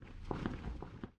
catch_air_8.ogg